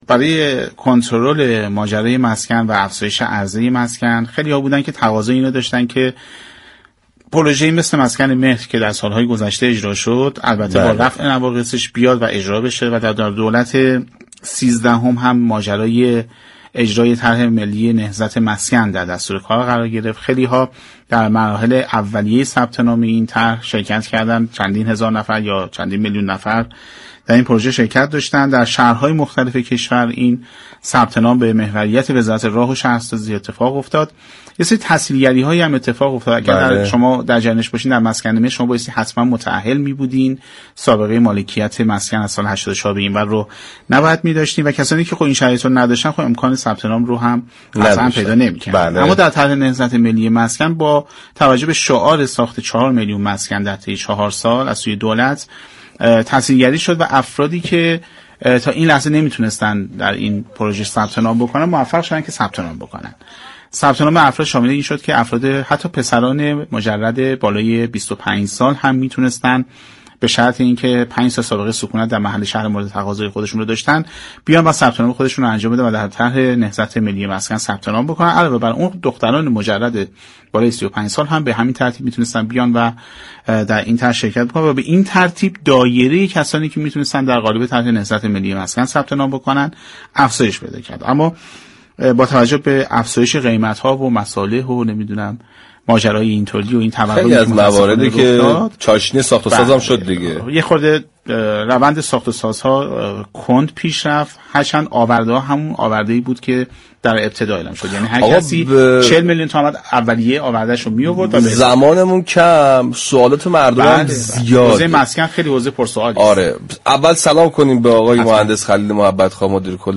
خلیل محبت خواه مدیركل راه و شهرسازی استان تهران در گفتگو با برنامه «سعادت آباد»